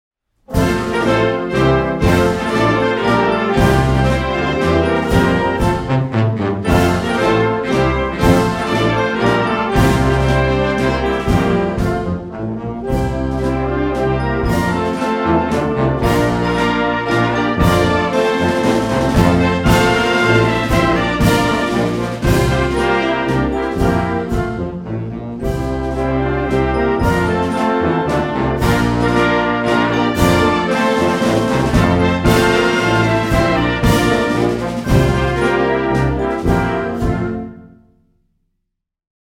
Wersja-instrumentalna-zwrotka-refren-2-wersja.mp3